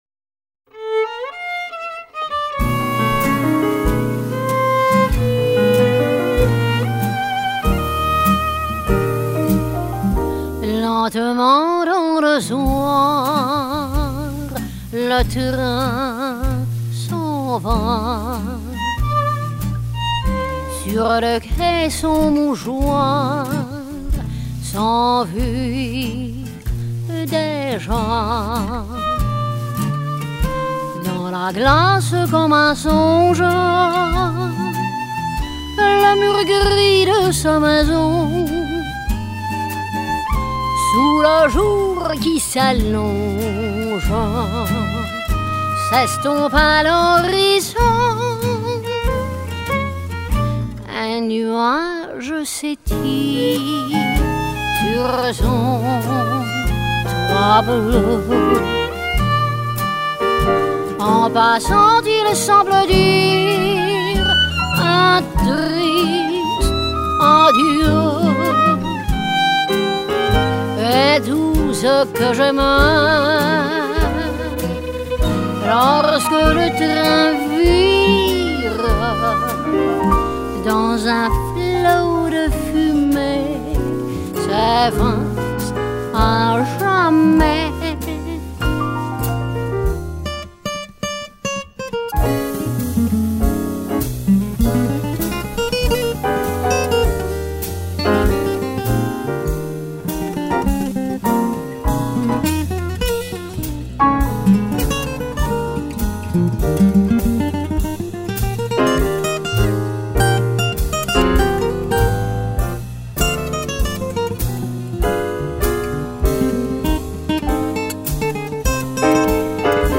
Жанр: Gypsy Jazz, Latin Jazz, Swing, Vocal Jazz